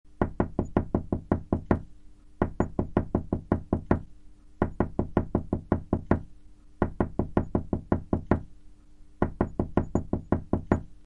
Download Knocking sound effect for free.
Knocking